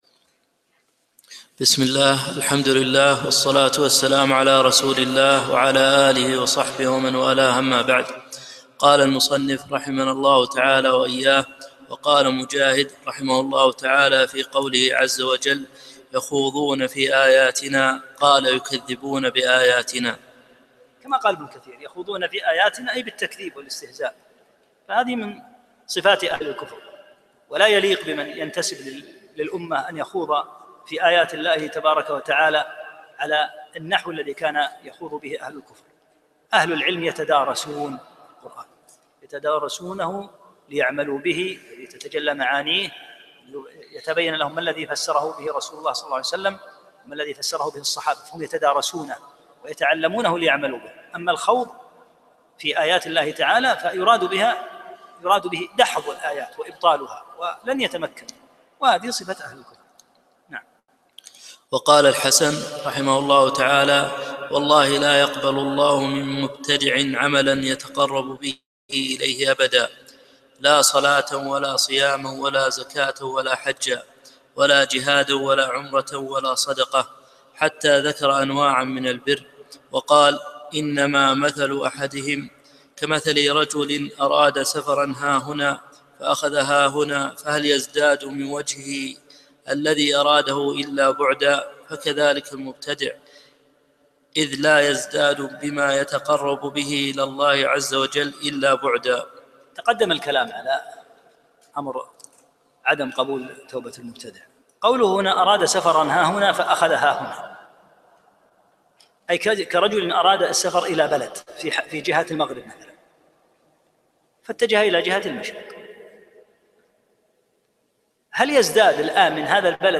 14- الدرس الرابع عشر